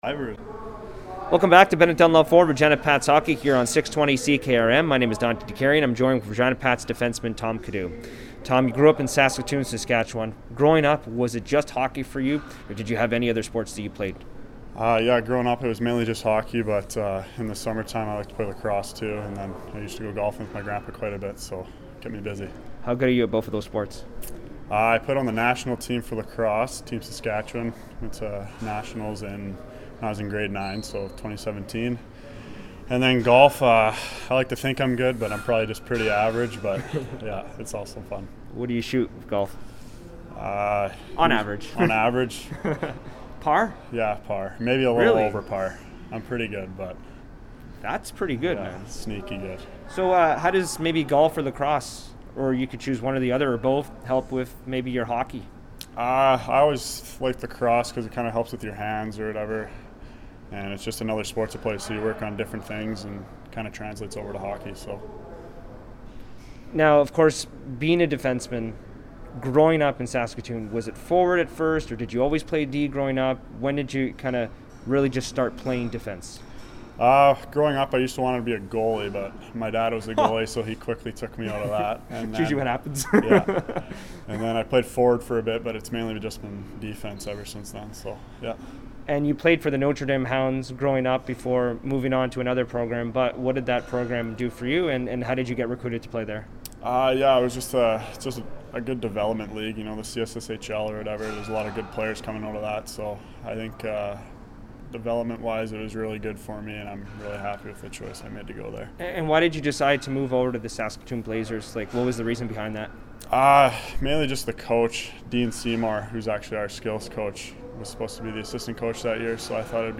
Radio Broadcast Interviews 2021-22 - Regina Pats